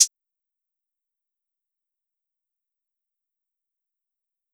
Closed Hat (Portland).wav